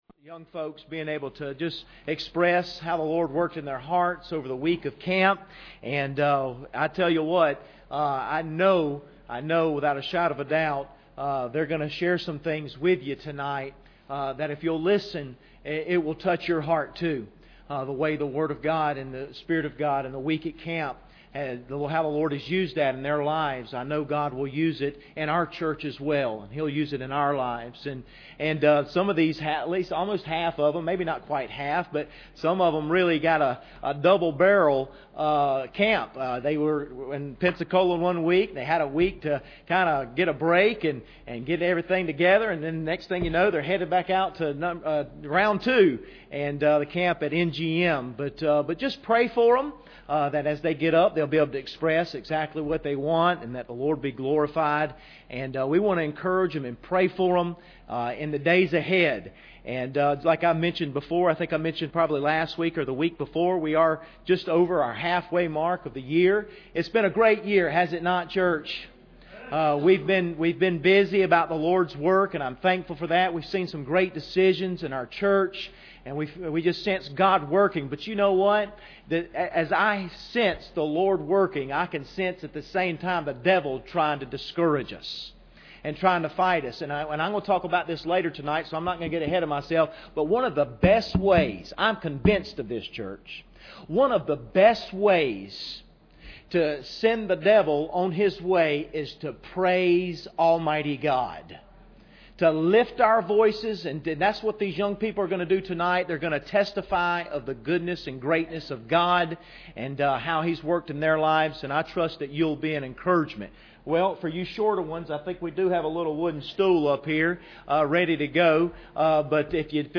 2017 NGM Testimonies
Preacher: CCBC Members | Series: General